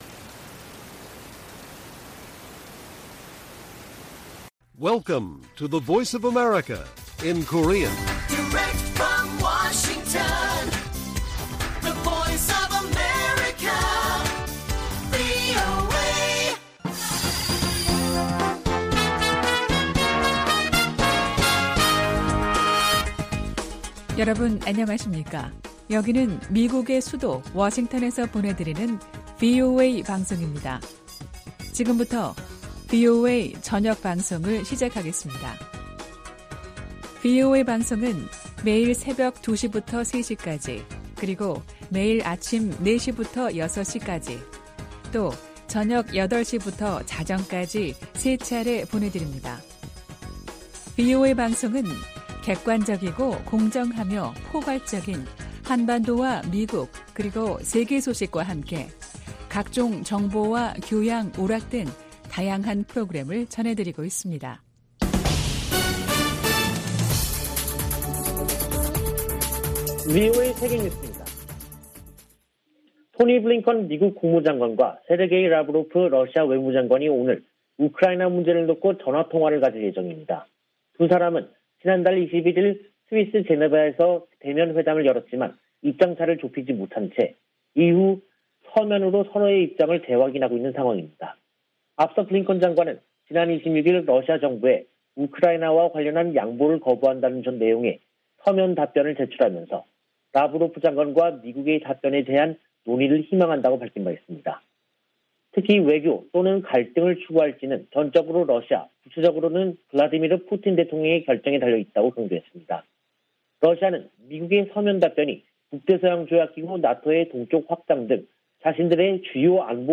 VOA 한국어 간판 뉴스 프로그램 '뉴스 투데이', 2022년 2월 1일 1부 방송입니다. 미 국무부는 북한의 거듭된 미사일 발사에 대해 책임을 묻기 위한 다른 조치들을 추진 중이라고 밝혔습니다. 미 국방부는 북한의 미사일 도발 중단과 안보리 결의 준수를 바란다고 밝혔습니다. 문재인 한국 대통령은 북한 미사일 발사로 인한 긴장이 "2017년도 시기와 비슷한 양상을 보이고 있다"고 최근 국가안전보장회의에서 평가했습니다.